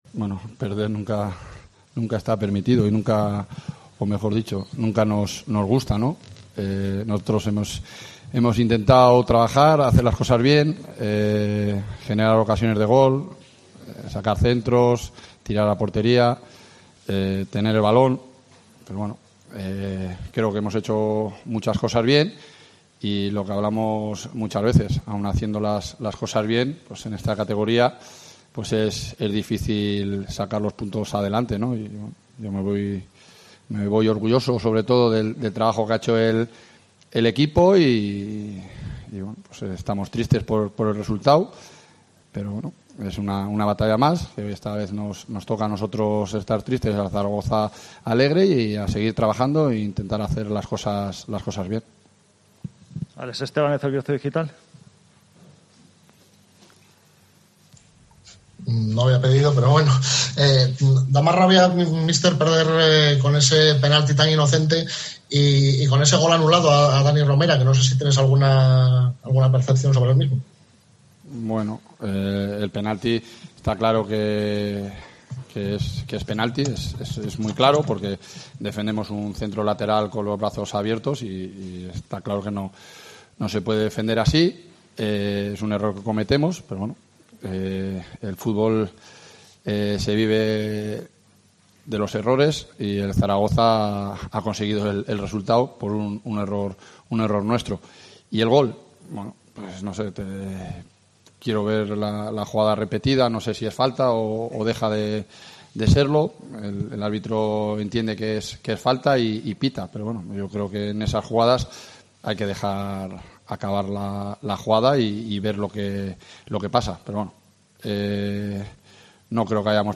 Escucha aquí las declaraciones del entrenador de la Deportiva Ponferradina, Jon Pérez Bolo, y del míster del Real Zaragoza, Juan Ignacio Martínez